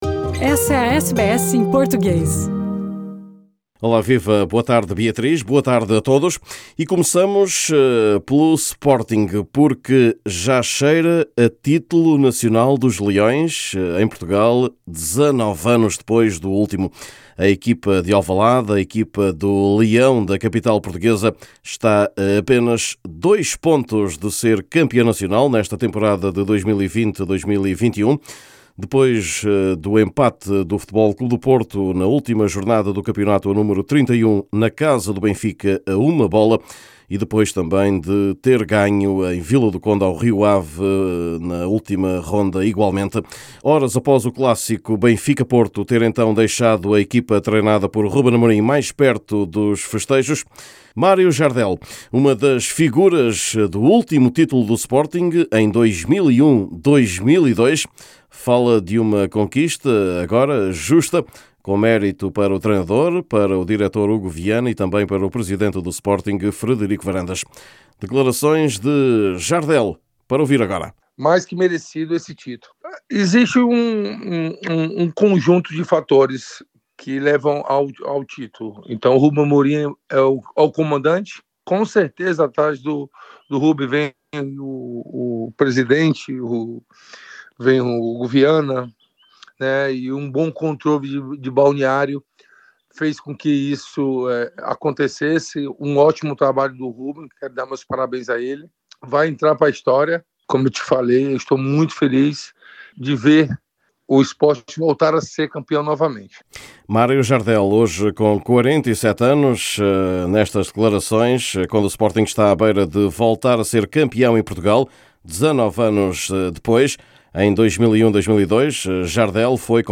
Neste boletim semanal